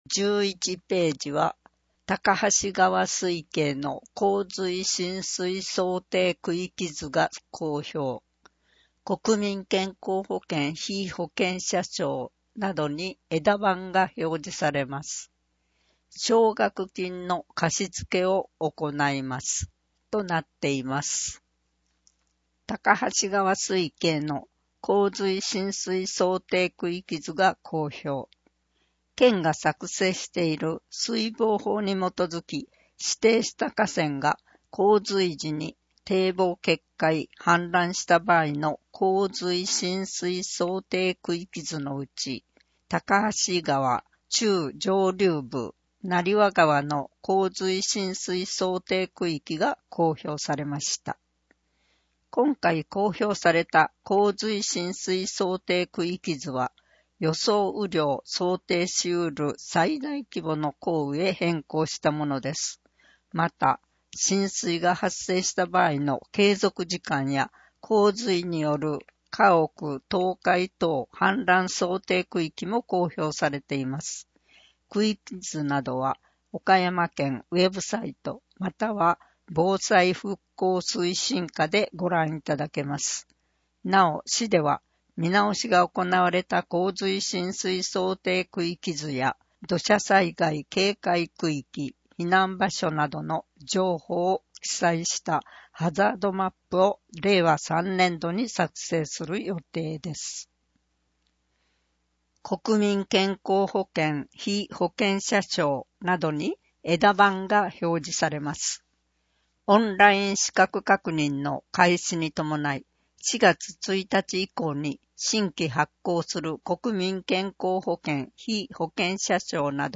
声の広報 広報たかはし令和3年3月号（197号）